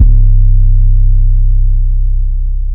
metro 808.wav